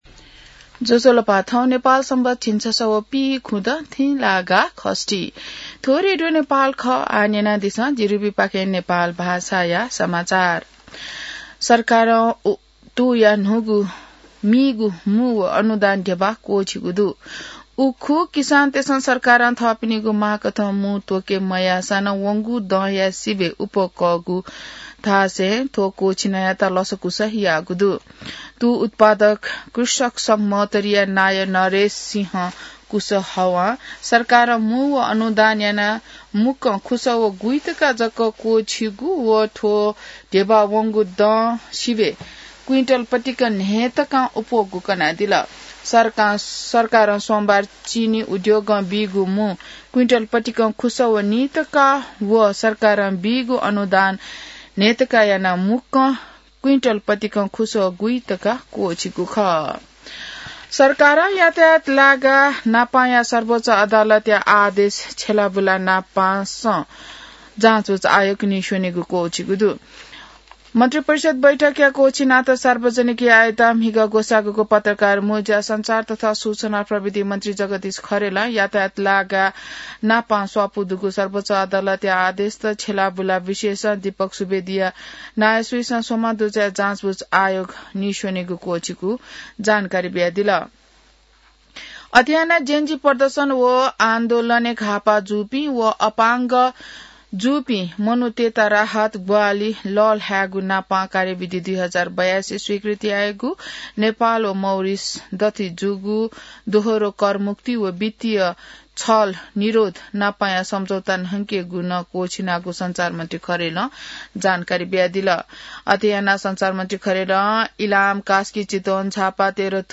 नेपाल भाषामा समाचार : २४ मंसिर , २०८२